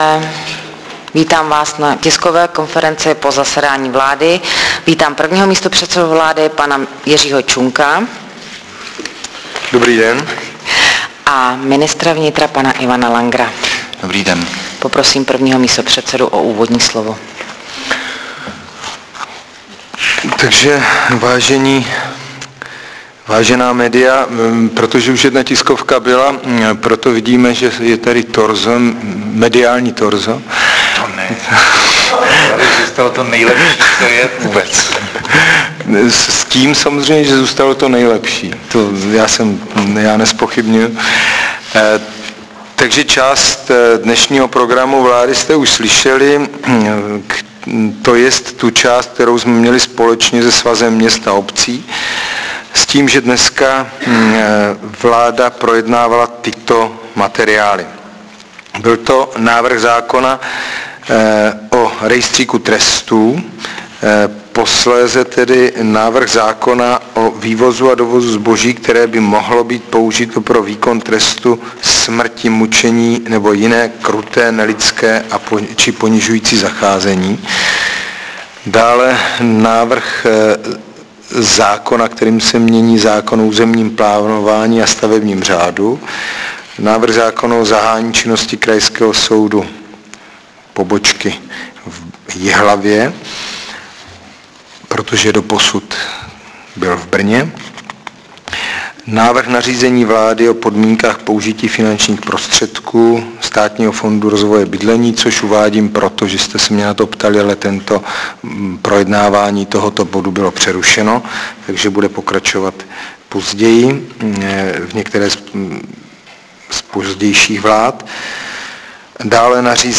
Tisková konference po zasedání vlády ČR 18.7.2007